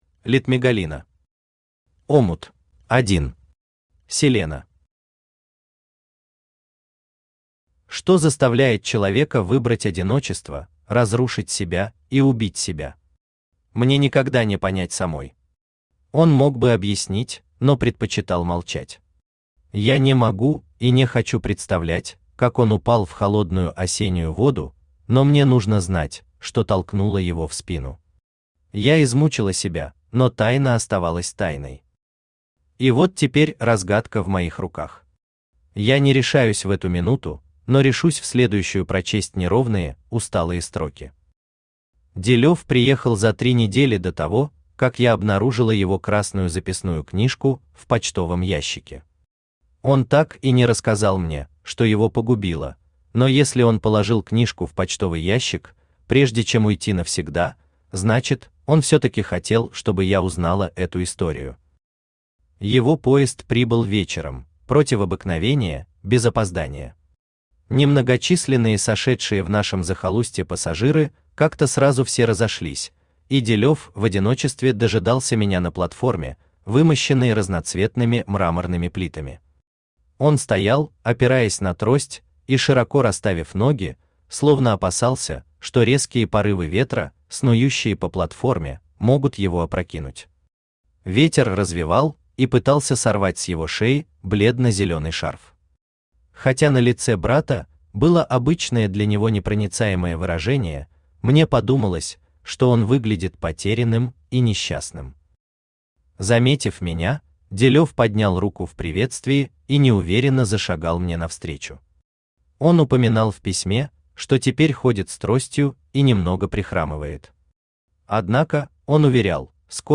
Аудиокнига Омут | Библиотека аудиокниг
Aудиокнига Омут Автор Литтмегалина Читает аудиокнигу Авточтец ЛитРес.